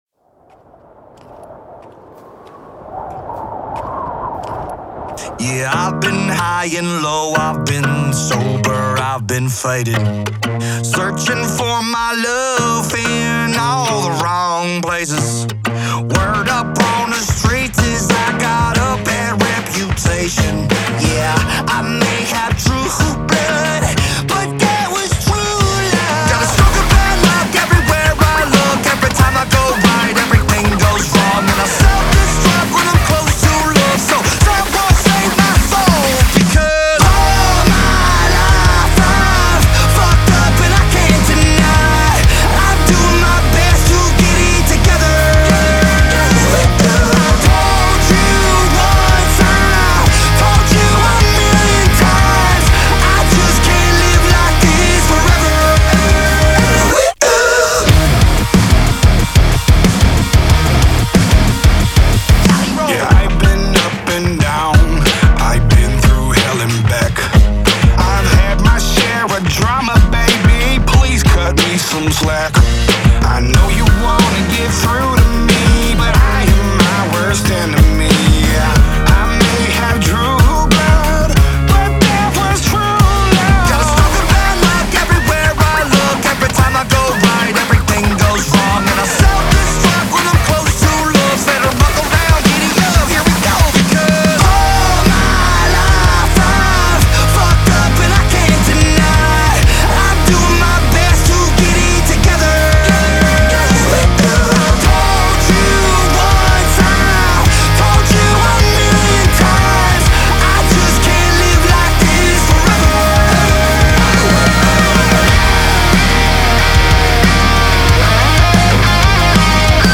Трек размещён в разделе Зарубежная музыка / Рок.